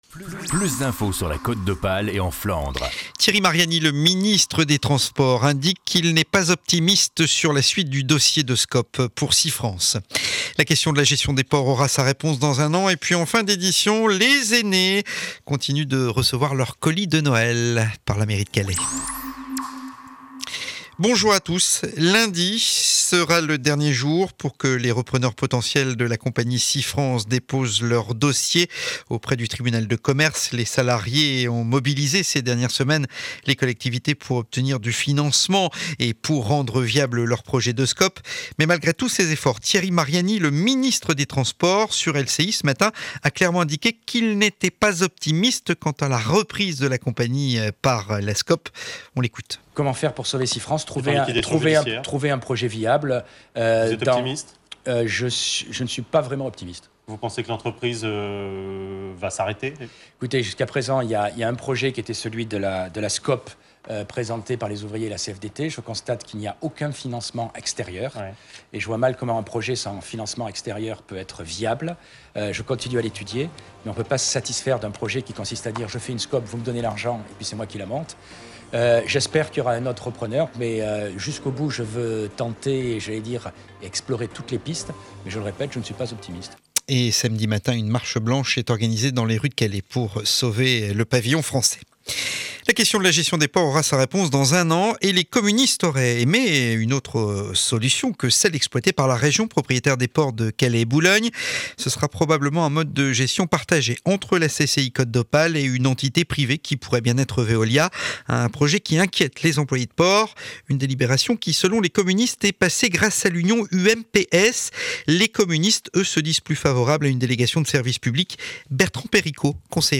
Journal de 12h00 du Jeudi 8 Décembre, édition de Calais.